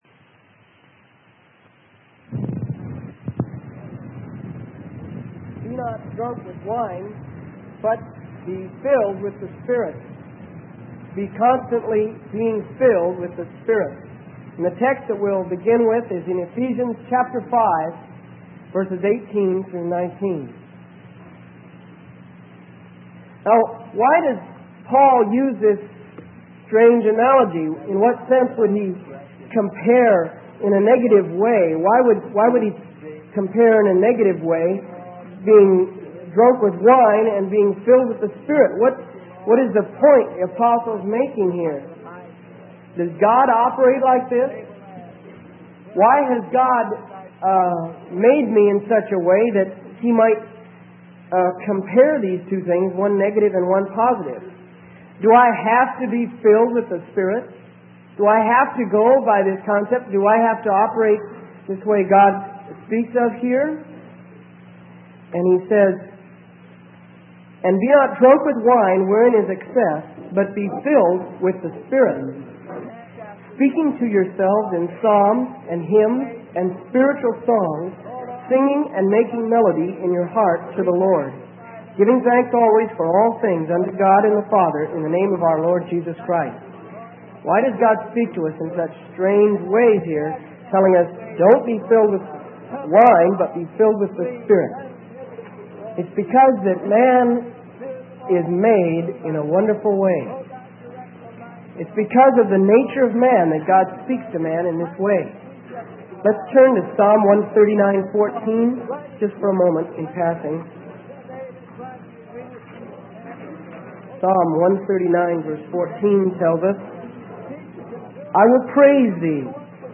Sermon: Be Not Drunk with Wine - Freely Given Online Library